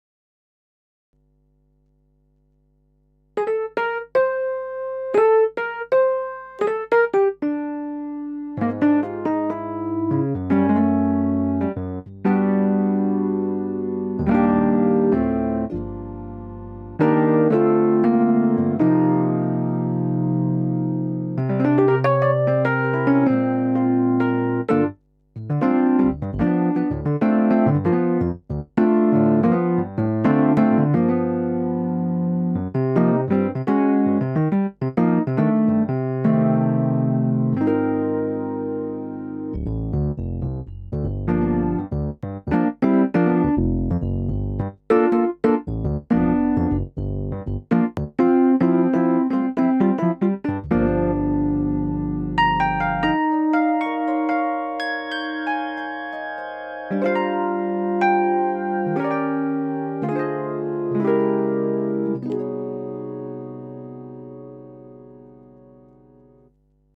Here's a little PC3-through Lexicon (MX400) with the PC3 playing a recent epiano experiment (clearly not all done yet) and the Lexicon (only $300 at Sweetwater) doing a phaser and a compressor. No preparation, no particular tune, but the idea is clear: